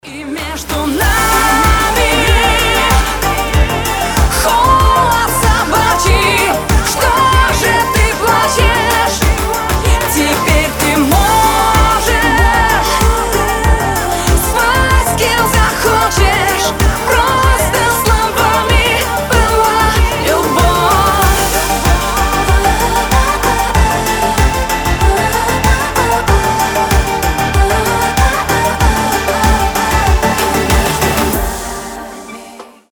• Качество: 320, Stereo
поп
женский вокал